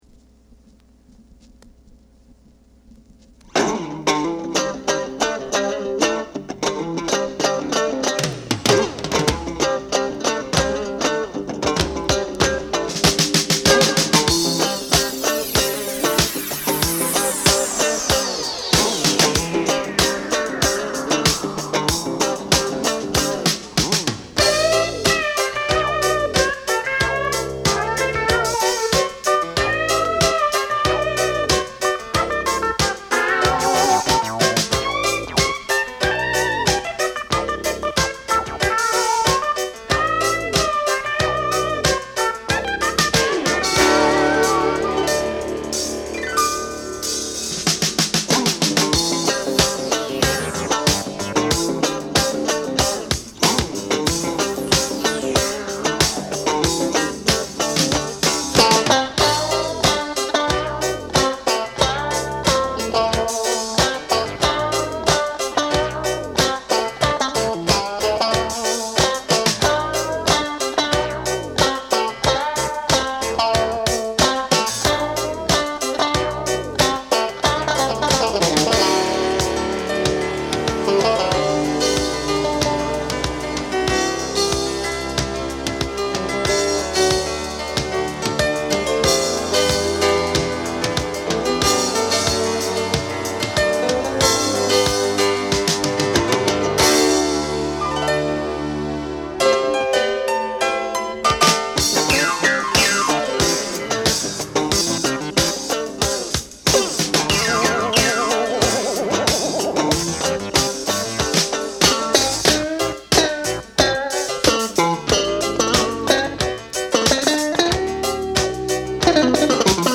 Genre: Jazz Fusion / Funk